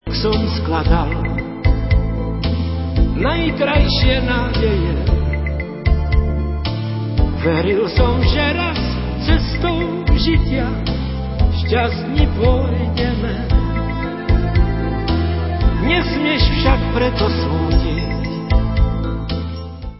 české pop-music